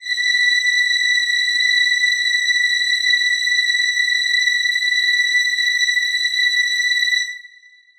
Choir Piano
B6.wav